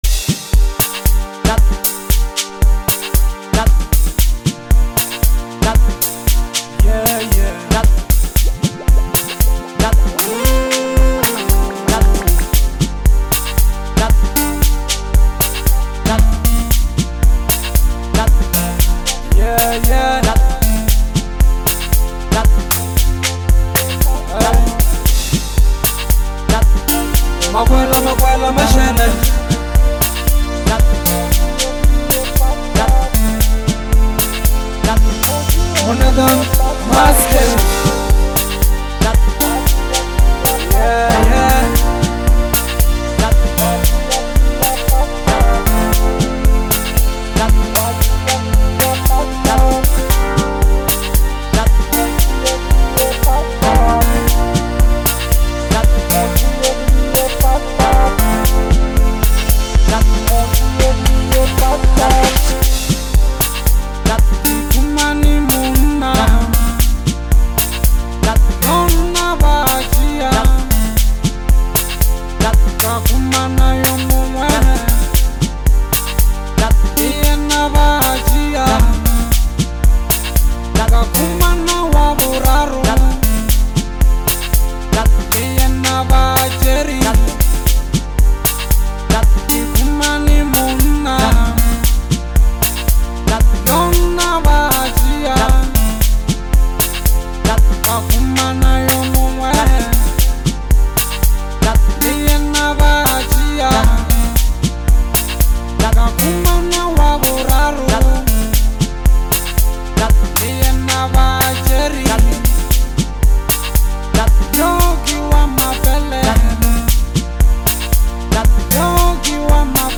South African record producer
Bolobedu House